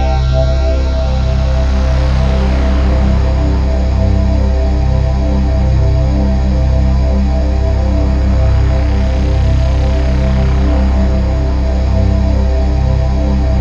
Index of /90_sSampleCDs/Infinite Sound - Ambient Atmospheres/Partition C/03-CHIME PAD
CHIMEPADC2-R.wav